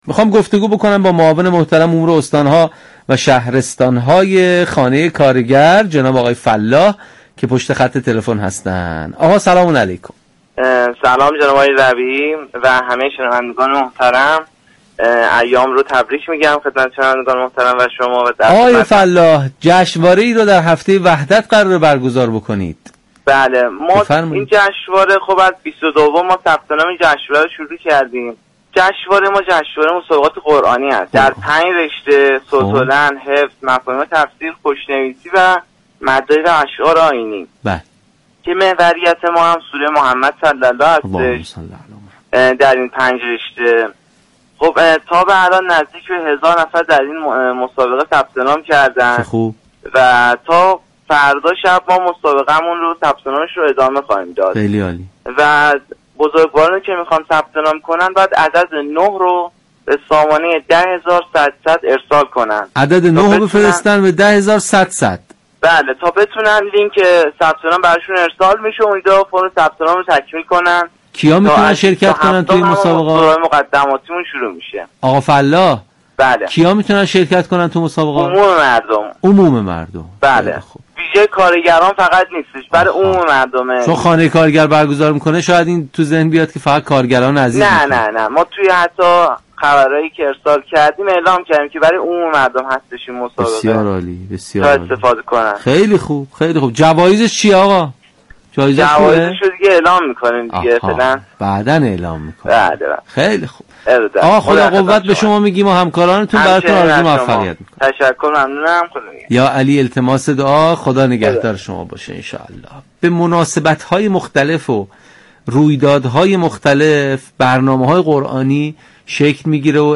در گفت‌وگو با برنامه تسنیم رادیو قرآن 25 مهر گفت
به صورت زنده از ساعت 7 تا 8 صبح از رادیو قرآن (موج اف ام، ردیف 100 مگاهرتز) پخش می‌شود.